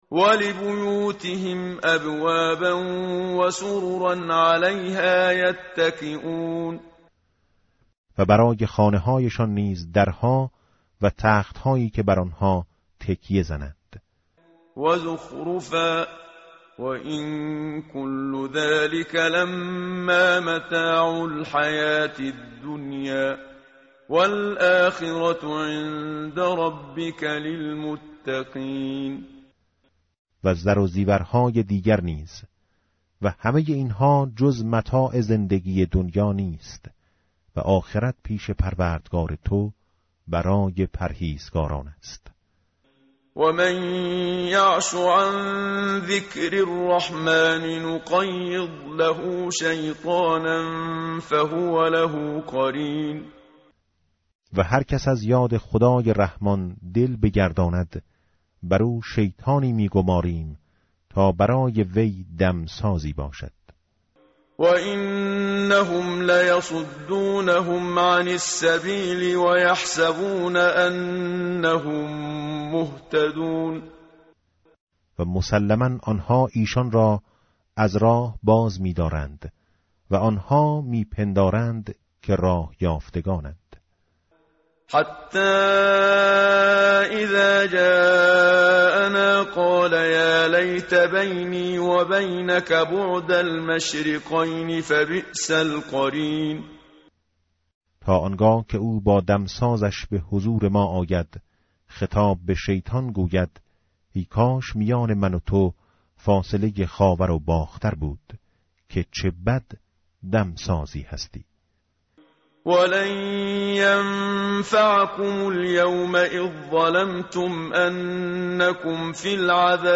متن قرآن همراه باتلاوت قرآن و ترجمه
tartil_menshavi va tarjome_Page_492.mp3